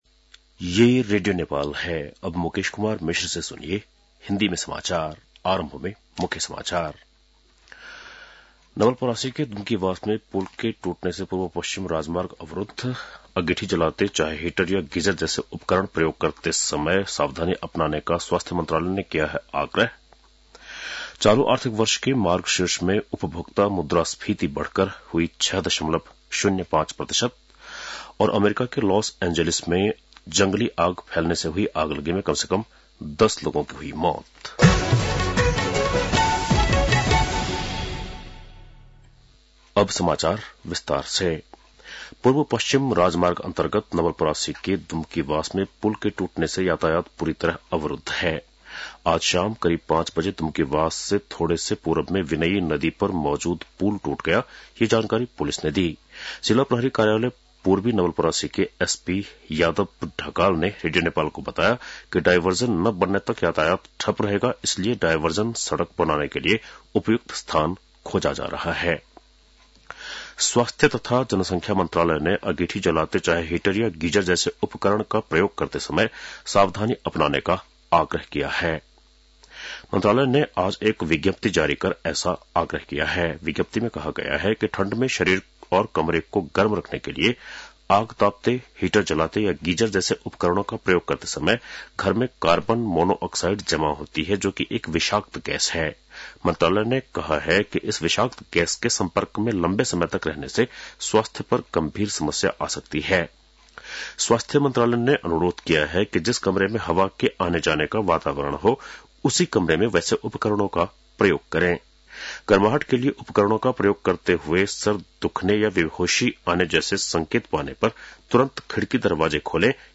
बेलुकी १० बजेको हिन्दी समाचार : २७ पुष , २०८१